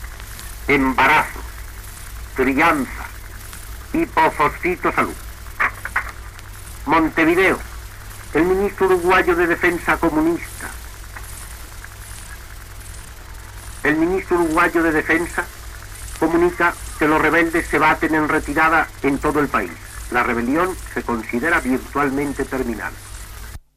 Publicitat, informació de la fi de la revolució a l'Uruguai
Informatiu